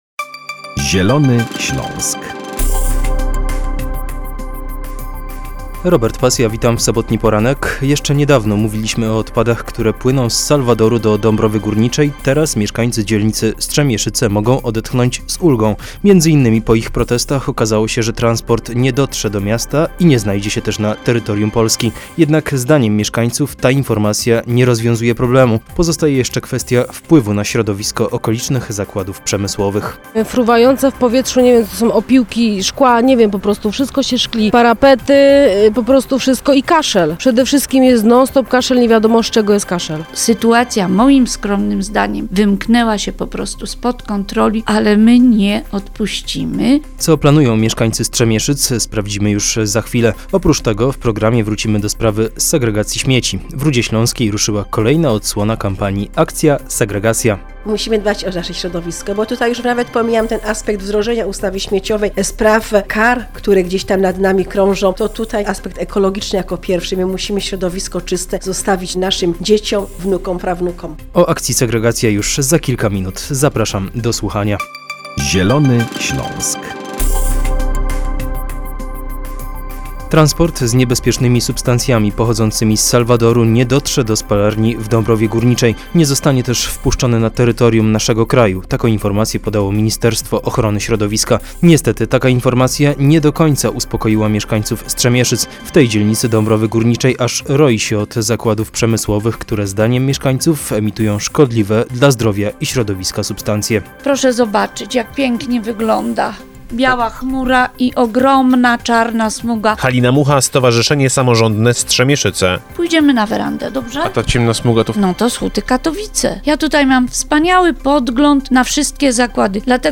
Dlatego też w Rudzie Śląskiej trwa kampania 'Akcja Segregacja' Dzięki niej mieszkańcy uczą się postaw ekologicznych. Na czym dokładnie polega kampania - o tym w relacji naszego reportera. W programie też informacja z Gliwic, gdzie otwarto Centrum Edukacji Ekologicznej "Łabędź".